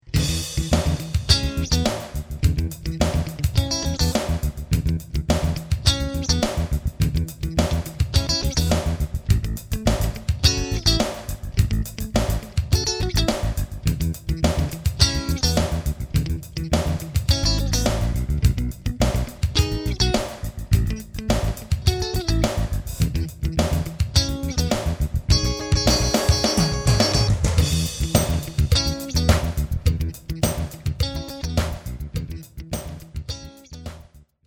Funky Blues
L'esempio sul quale ci apprestiamo a lavorare, che possiamo intitolare Funky one blue, è in pratica un blues a dodici battute, la cui armonia è costituita da questa successione di accordi: